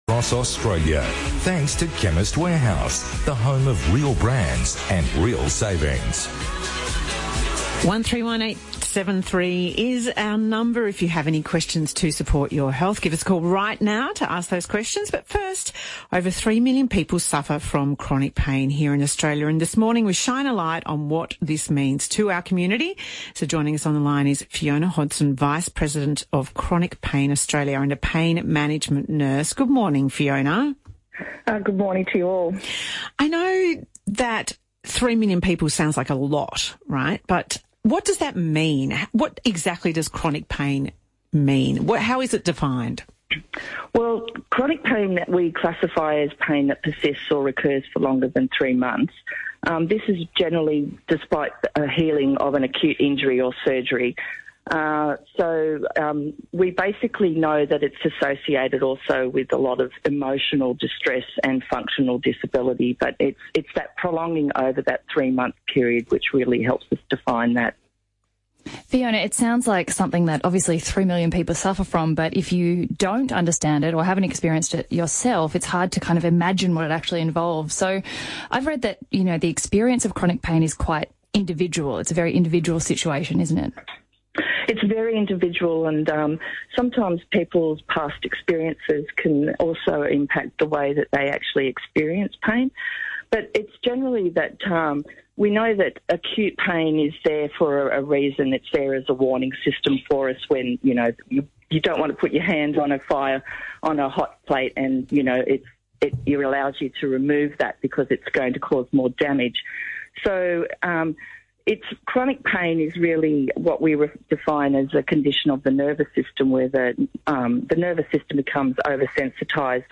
chronic-pain-interview.mp3